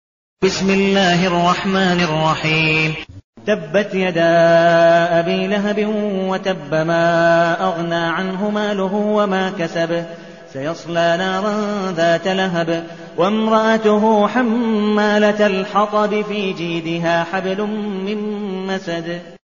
المكان: المسجد النبوي الشيخ: عبدالودود بن مقبول حنيف عبدالودود بن مقبول حنيف المسد